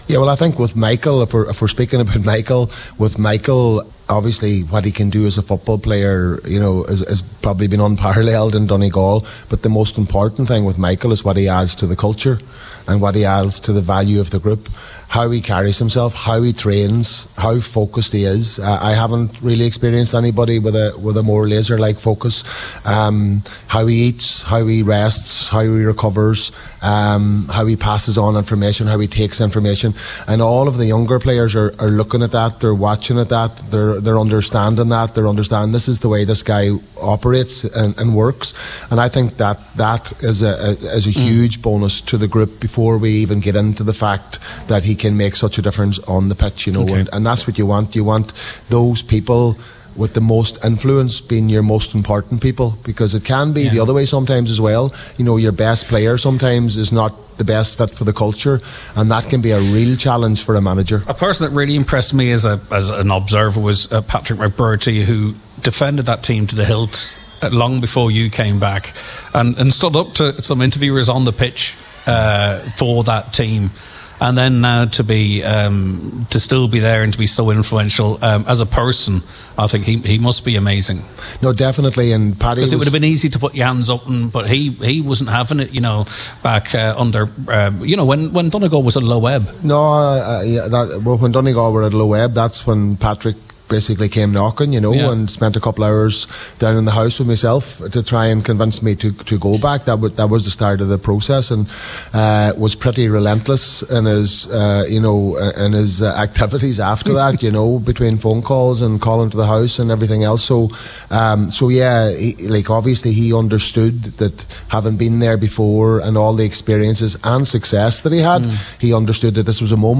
Nine-Til-Noon show which broadcast live from the Mount Errigal Hotel as part of Local Enterprise Week.